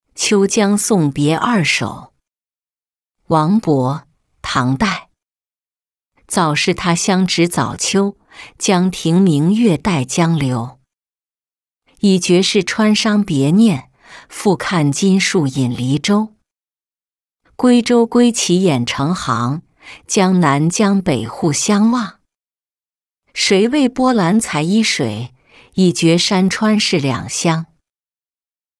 秋江送别二首-音频朗读